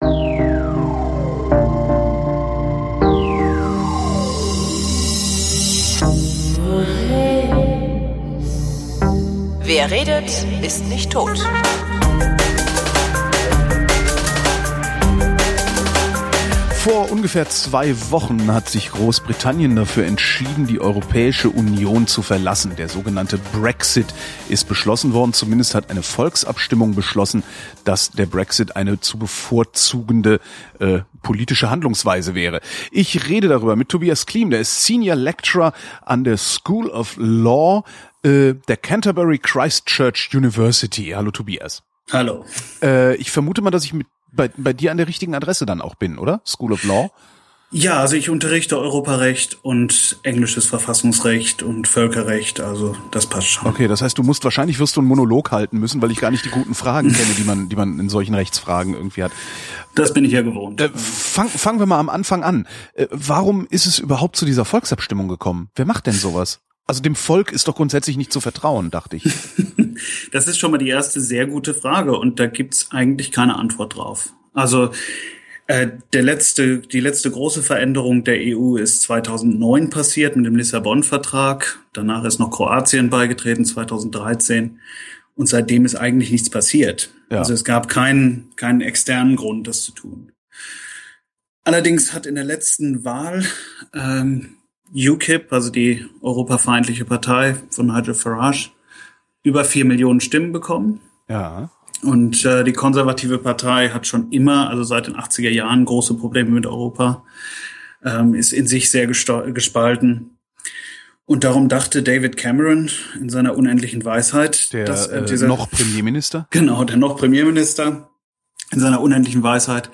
Das Pfeifgeräusch in der Aufnahme bitte ich zu entschuldigen